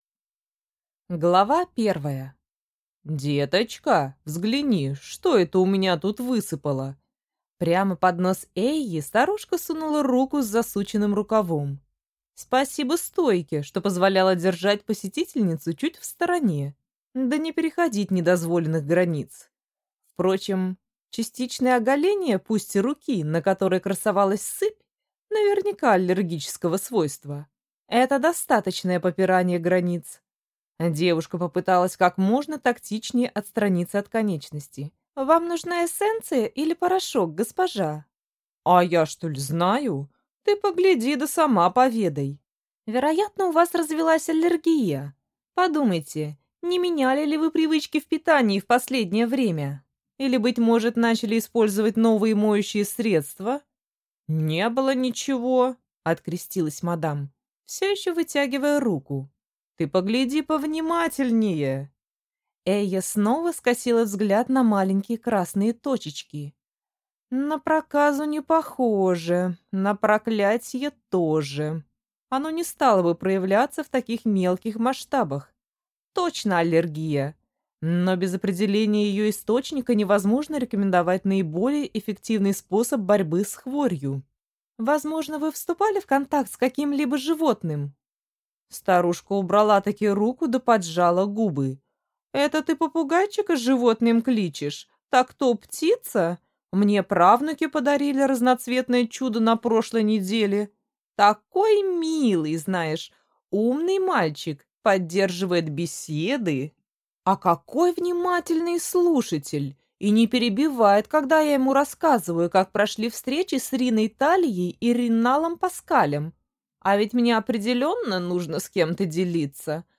Аудиокнига Зельевая лавка госпожи Эйи, или Упс! Я случайно!